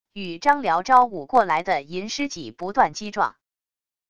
与张辽招舞过来的银狮戟不断击撞wav音频生成系统WAV Audio Player